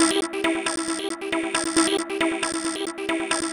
Em (E Minor - 9A) Free sound effects and audio clips
• techno synth sequence 136 6.wav
techno_synth_sequence_136_6_VSN.wav